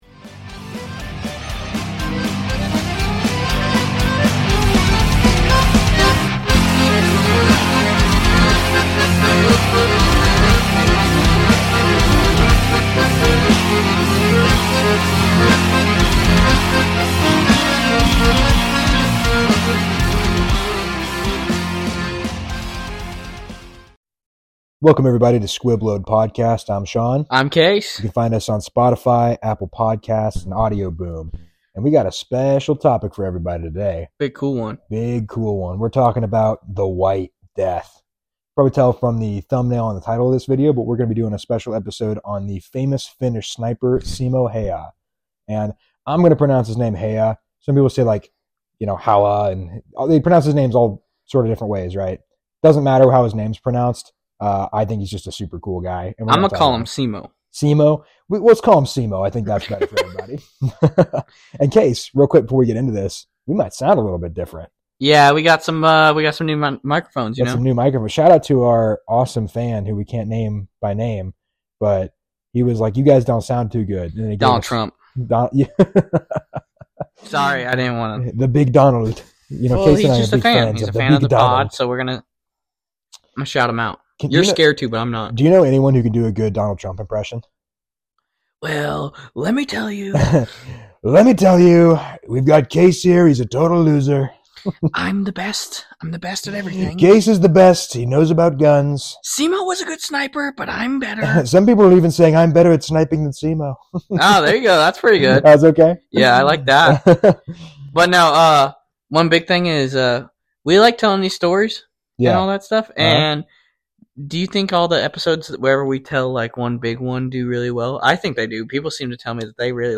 Editors Note - Sorry about the audio on this one, we are still figuring out the best setup for our microphones.